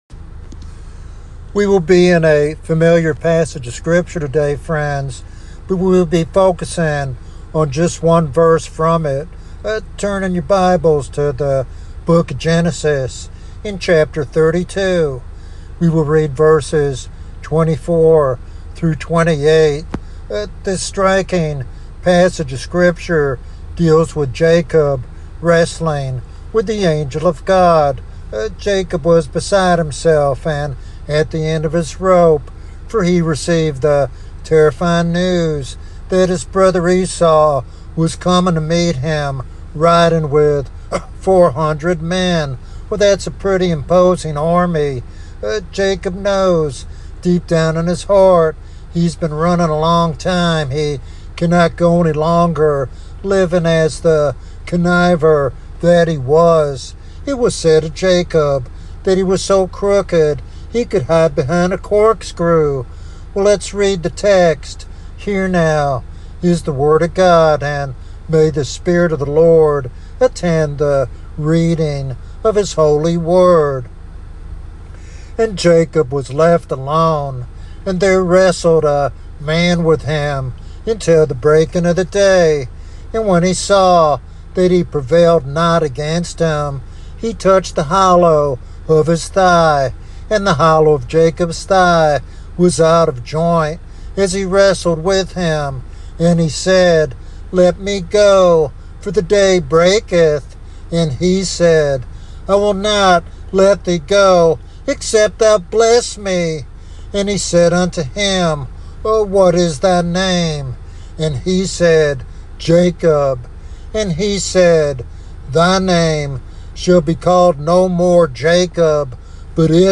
This sermon challenges the church to recognize the scarcity of godly leaders today and encourages believers to rise as princes in God's kingdom.